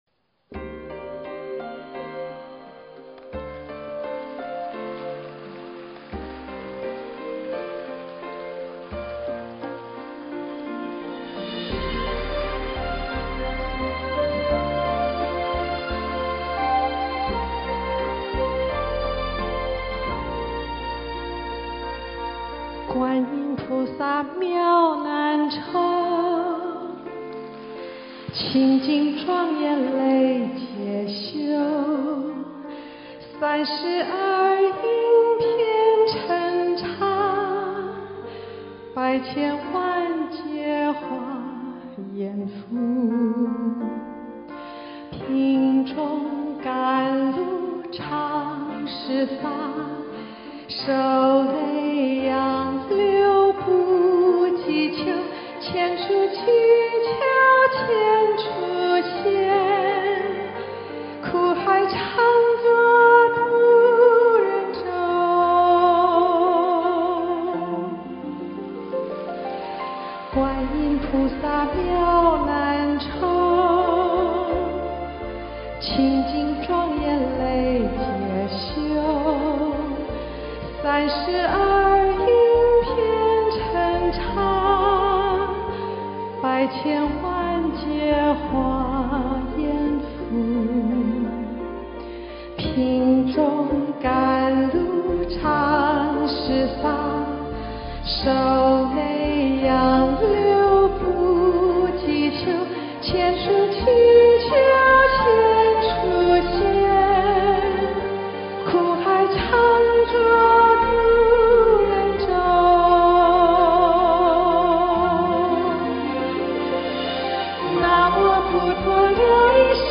音频：2018年十周年台庆精彩回放－齐豫精彩献唱《观音菩 薩偈》